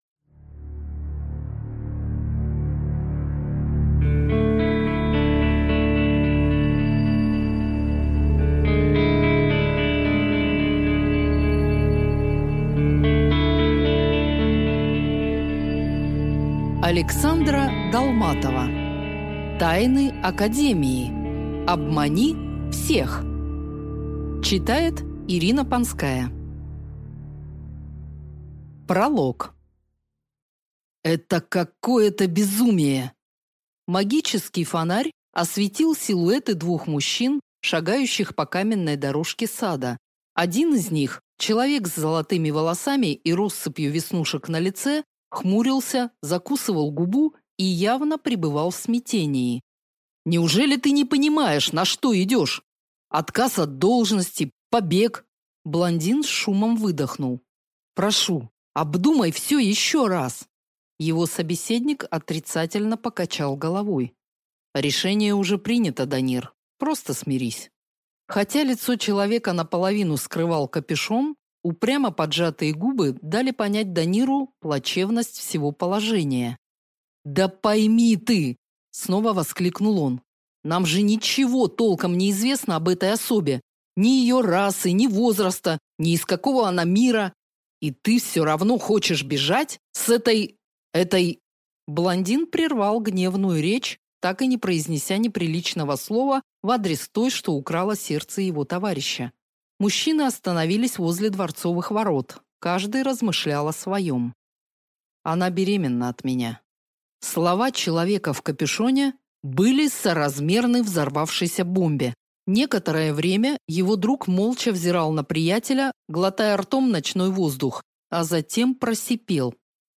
Аудиокнига Тайны Академии. Обмани всех | Библиотека аудиокниг